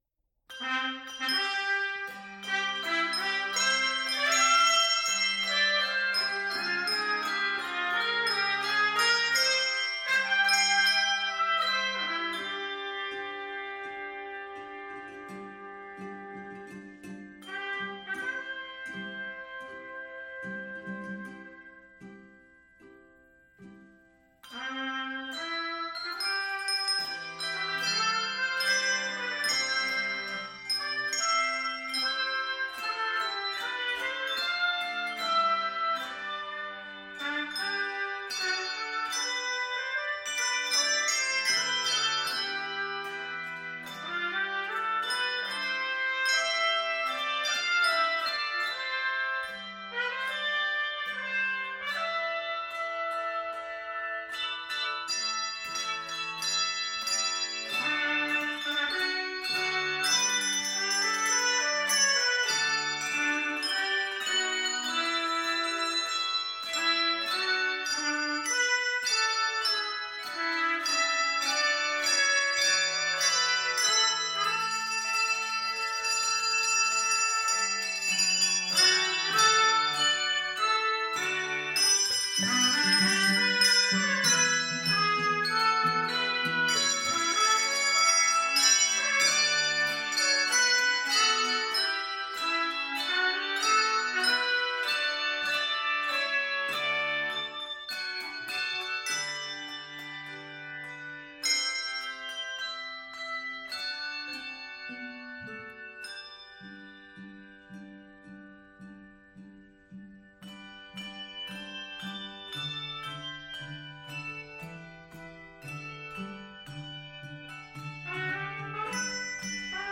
triumphant and exciting medley